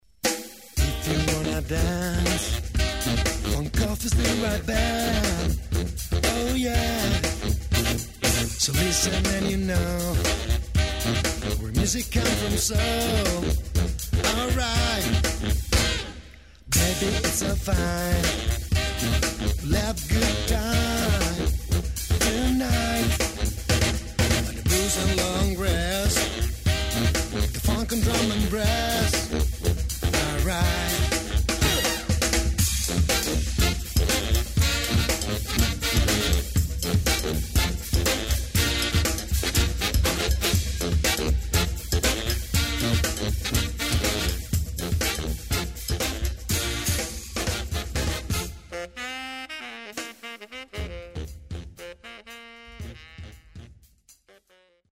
the one and only funky marchin' band